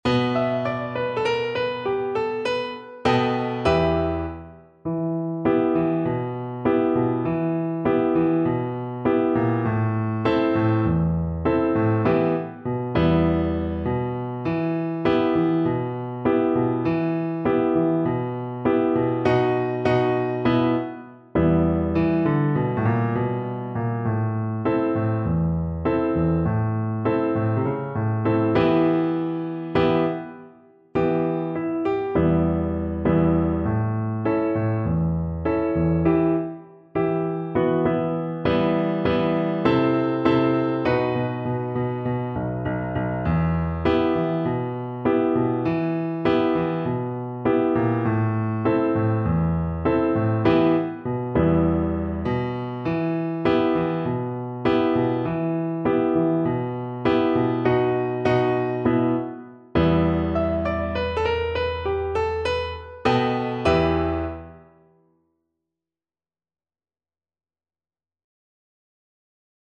Free Sheet music for Soprano (Descant) Recorder
4/4 (View more 4/4 Music)
Medium Blues = 100